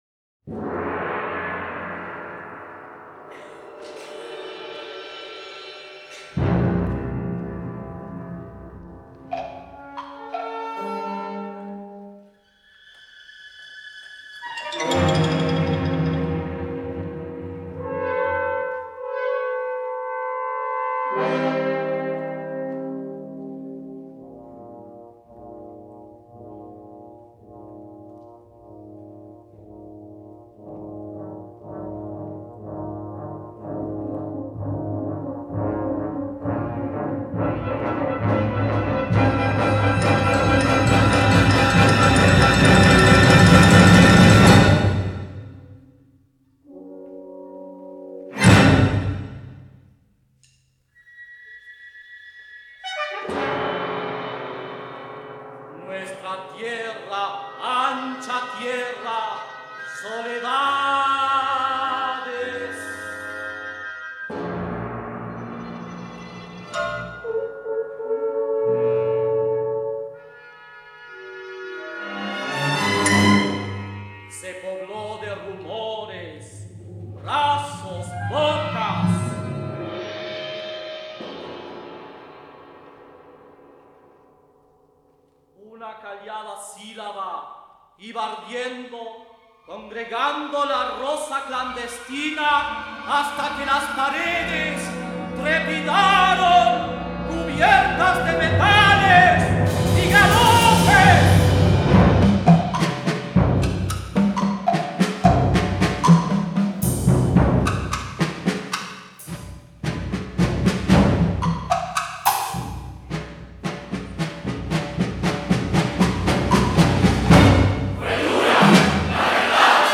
América Insurrecta (para tenor, coro y orquesta)